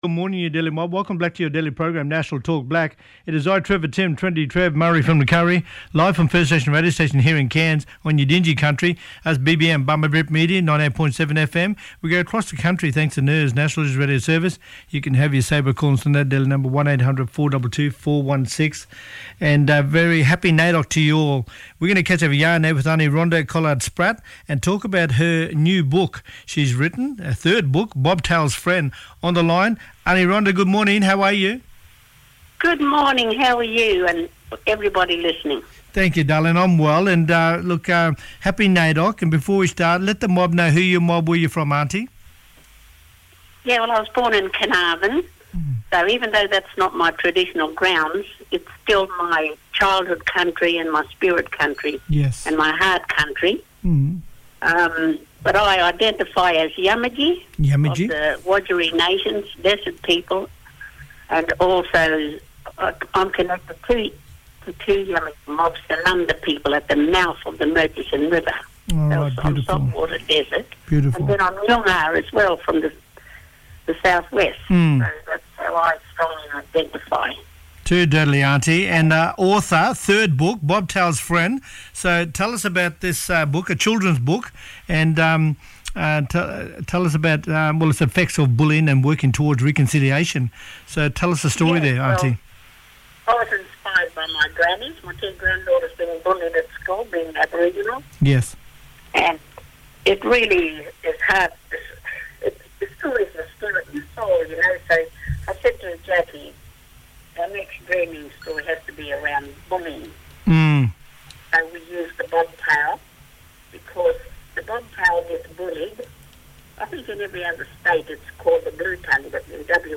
Listen back on our Talkblack Program live from the Cairns Show!